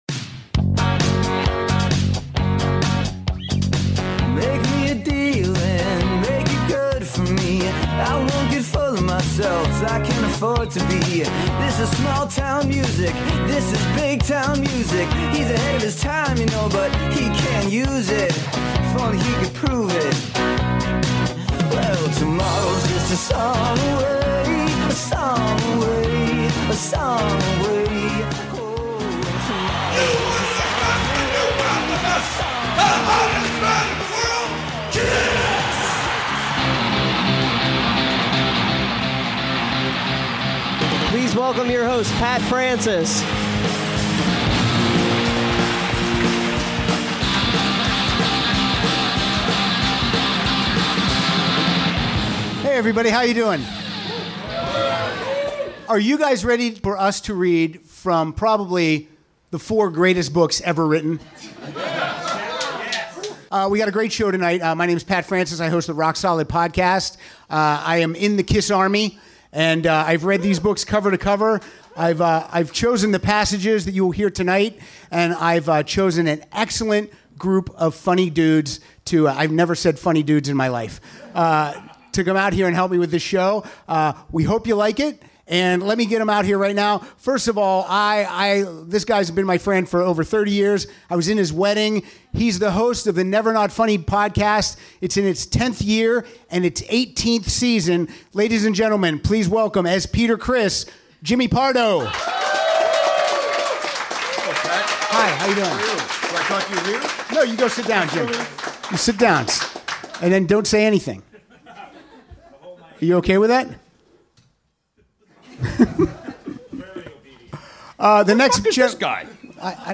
This show was recorded in front of a LIVE audience on July 19, 2016 at the NerdMelt Showroom in Hollywood, CA.